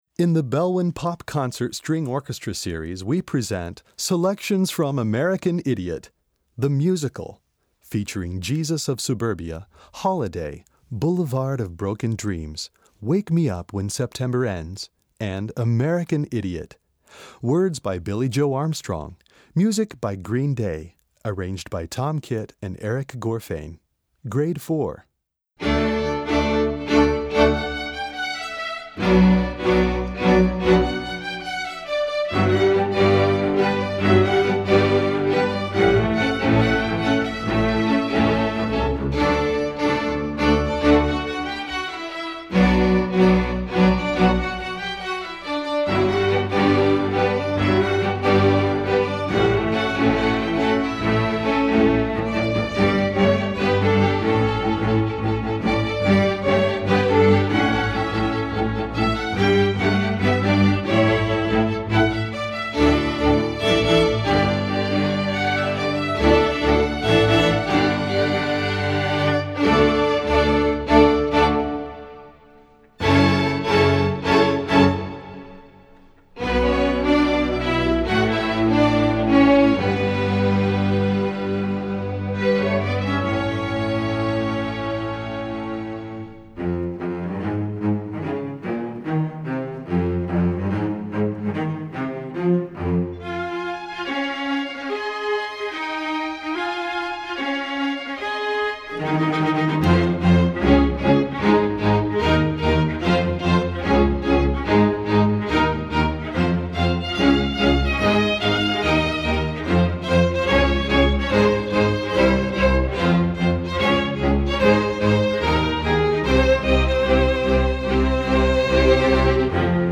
String Orchestra (MD)   Score